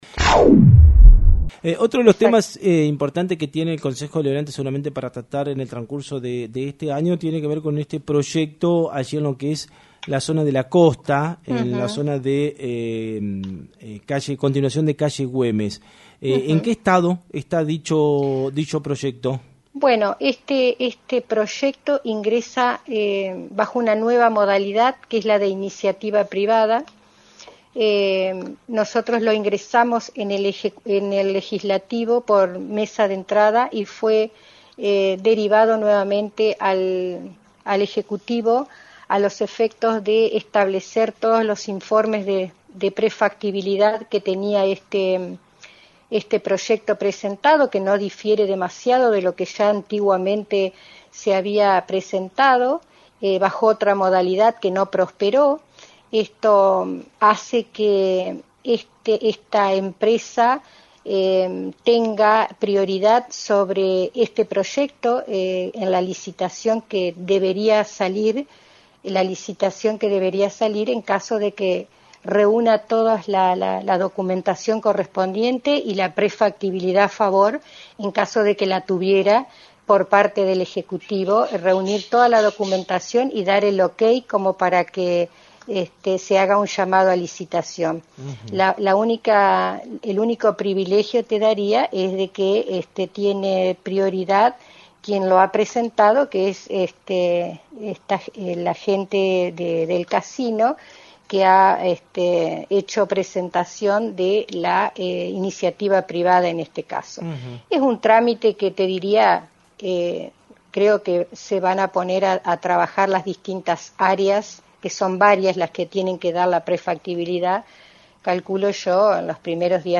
“No hay que repetir los errores que se dieron con la empresa Mision”, disparó de paso en la charla con los periodistas de FM 90.3, y consideró que “los vecinos de Victoria van no se van a oponer al proyecto cuando lo conozcan….No hay que ver fantasmas donde no hay”.
Vice Intendente Ana Schuth